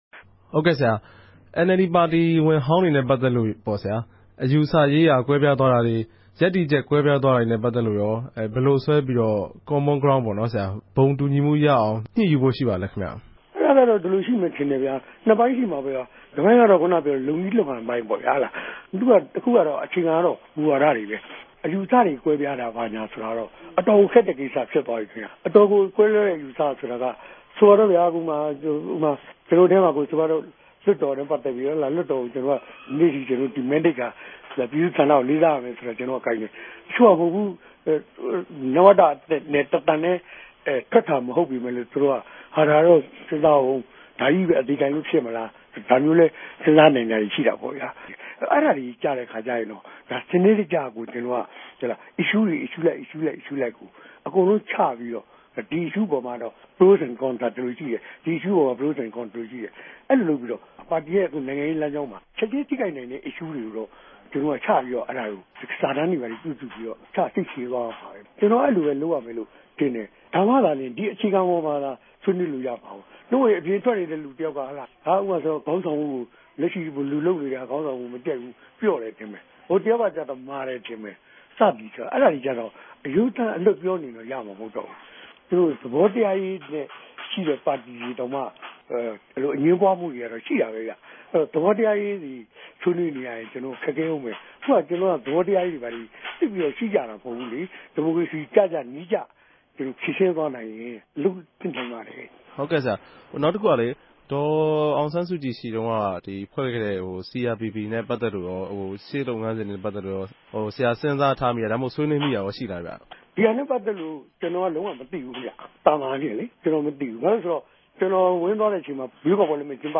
အပိုင်း (၂)မြာတော့ ူပည်သူႛလြတ်တော် ကိုယ်စားူပြကော်မတီ (CRPP) အဖြဲႚအပေၞ သဘောထား၊ အမဵိြးသား ူပန်လည်သင့်ူမတ်ရေးနဲႛ တြေႚဆုံဆြေးေိံြးရေး ကိင်္စတေနြဲႛ ပတ်သက်္ဘပီး မေးူမန်းထားတာတေကြို နားဆငိံိုင်ပၝတယ်။